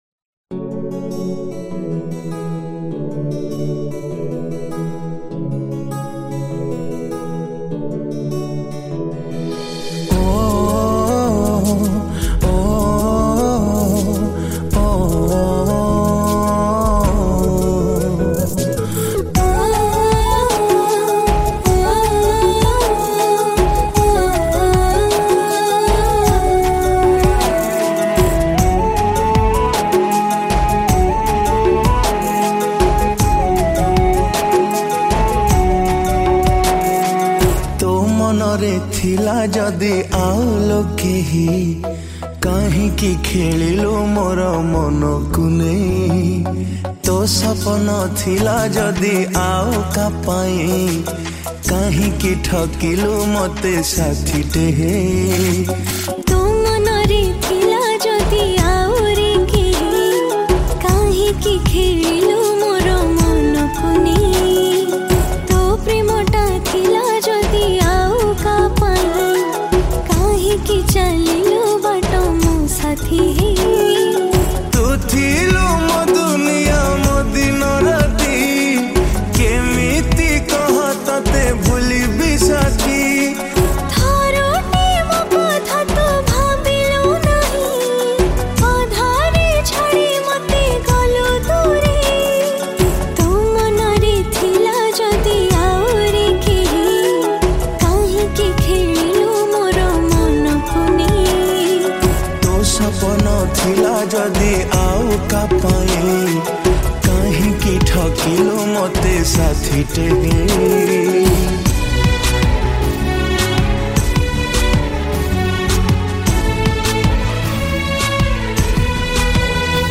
Odia Super Hit Songs